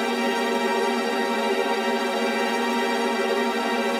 Index of /musicradar/gangster-sting-samples/Chord Loops
GS_TremString-Gdim.wav